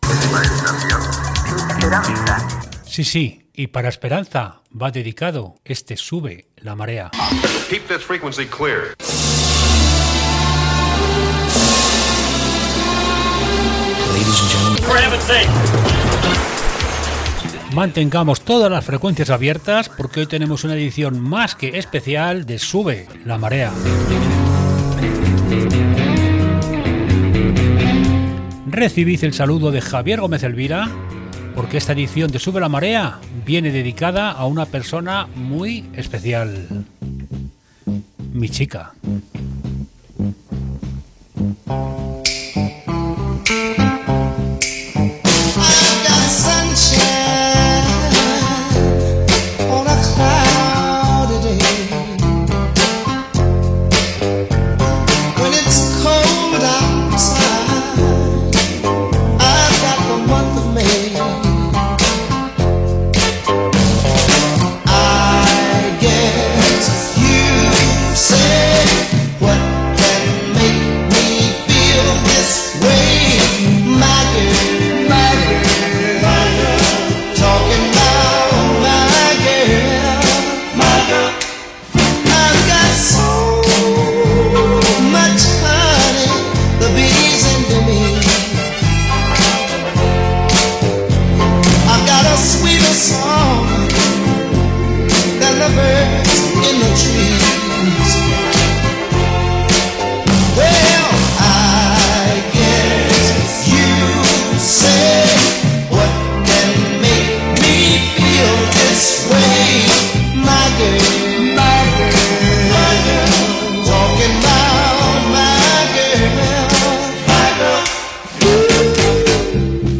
En Sube la Marea queremos dedicar esta edición a escuchar buen Soul y dedicárselo a una persona muy especial.